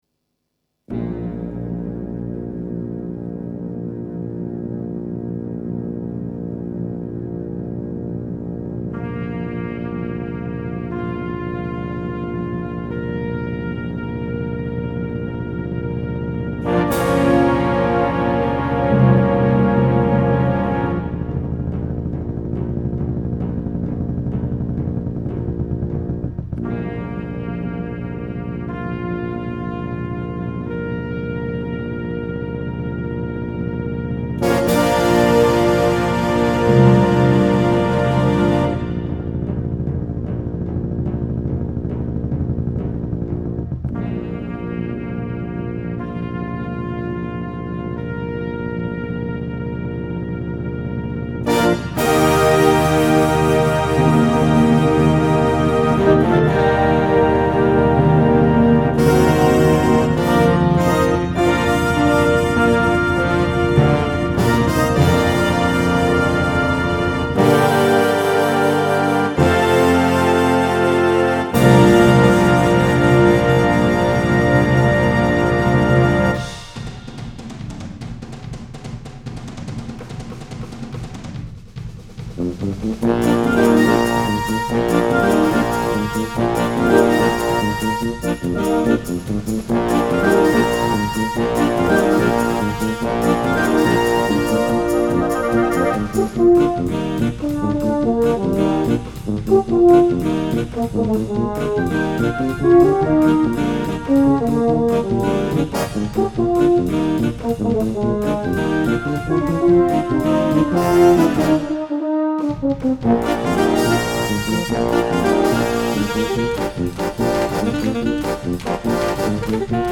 Für Blasorchester
Hit Medley
Ruhepunkt im Stil einer Rock-Symphony-Ballade
im bekannten Latin-Stil, als Trompeten-Feature